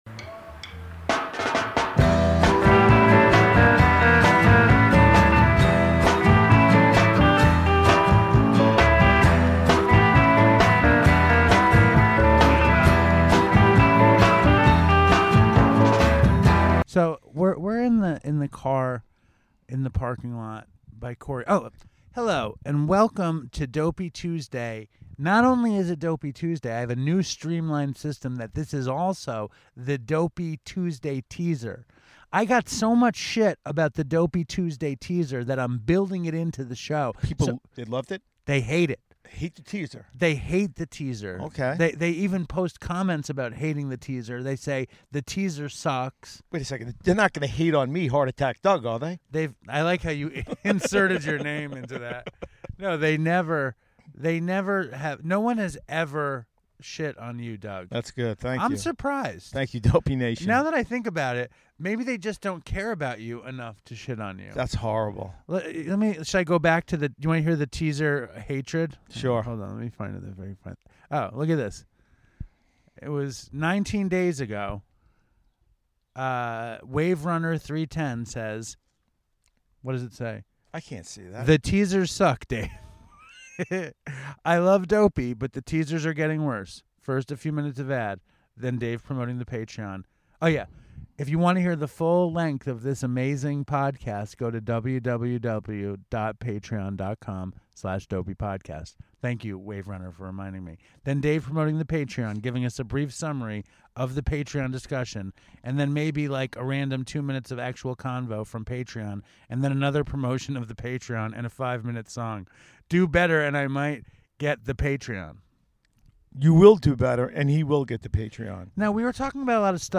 Comedy, Health & Fitness, Health & Fitness:mental Health, Mental Health, Alternative Health